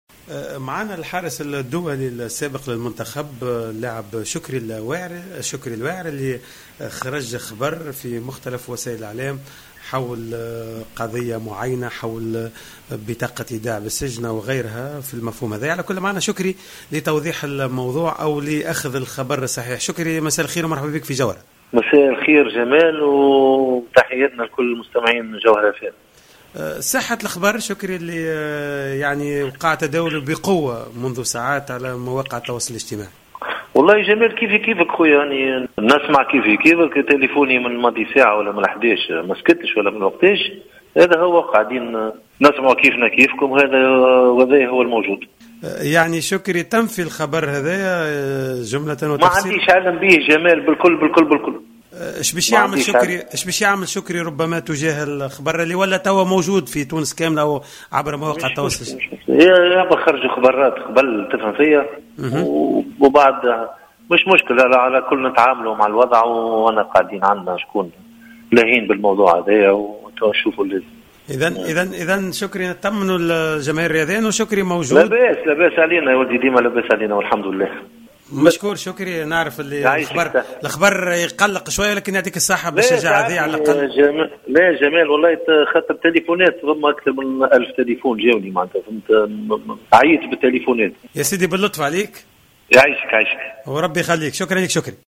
أفاد الحارس السابق للترجي الرياضي التونسي و المنتخب الوطني شكري الواعر في إتصال جمعه بجوهرة أف أم أن خبر صدور بطاقة ايداع بالسجن ضده لا اساس له من الصحة .